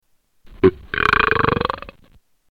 Wet buuuuurrrrrrpppppp